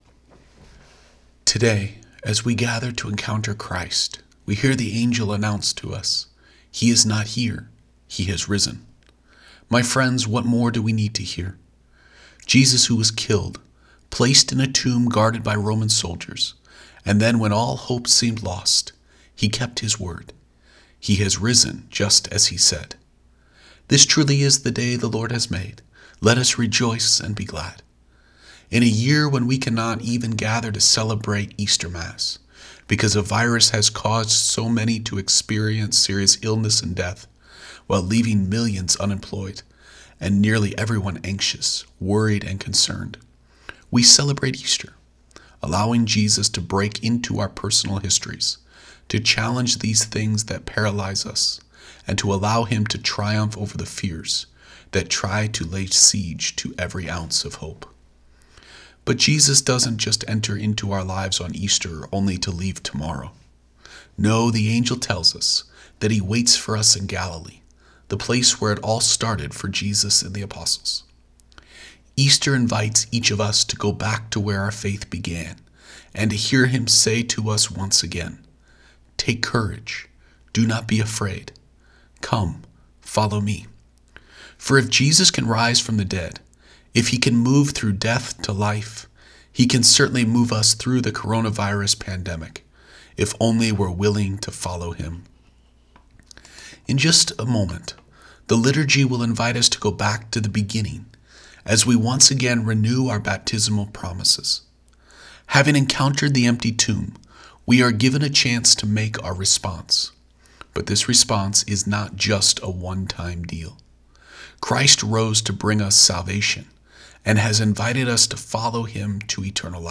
Posted in Homily